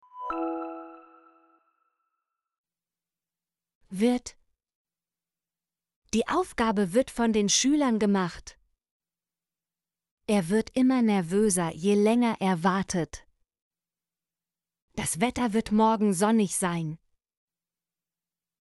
wird - Example Sentences & Pronunciation, German Frequency List